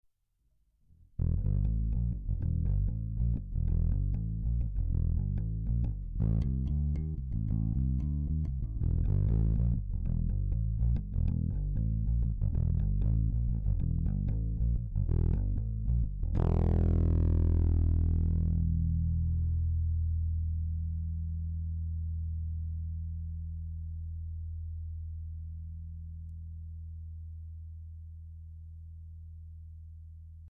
『Ibanez RD400』フロントとリアをジャズベみたいにシングル×シングルで使用。
ピッキングはフロントピックアップ近く。
これだけでけっこうサウンドキャラが変わってきやがります。